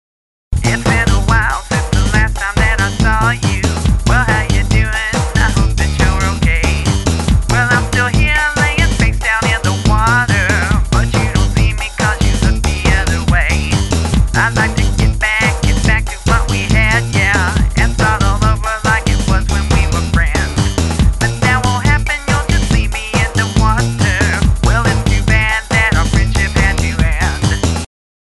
Original Music Samples With Vocals